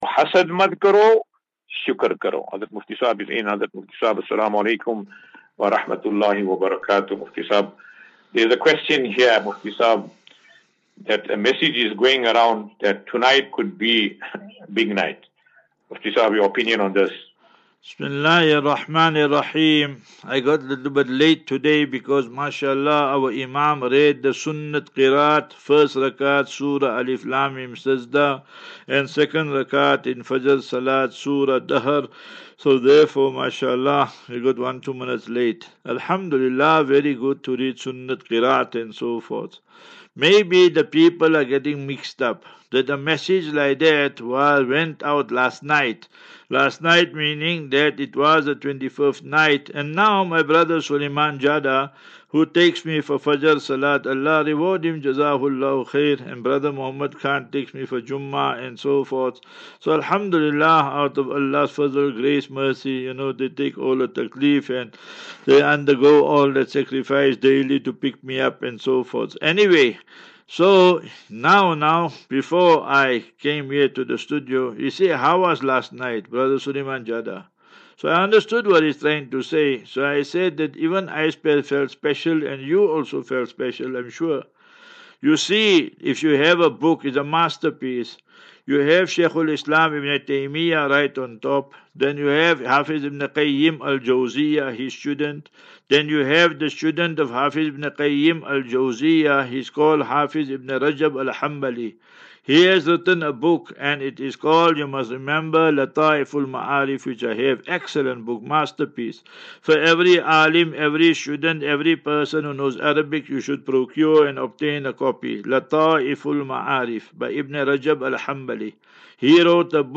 View Promo Continue Install As Safinatu Ilal Jannah Naseeha and Q and A 5 Apr 05 April 2024.